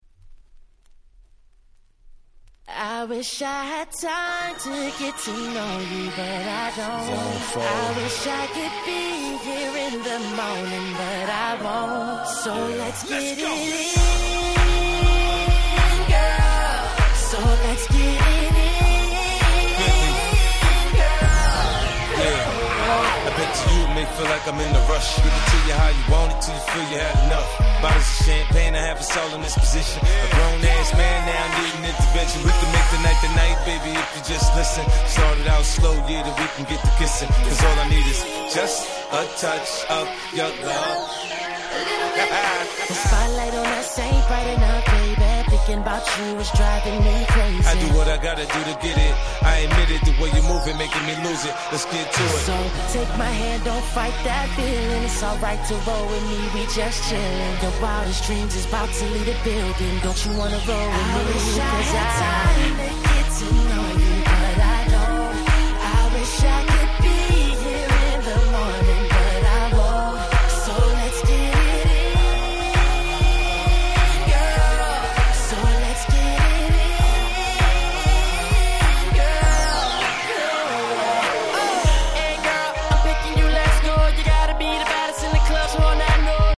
10' Super Hit R&B !!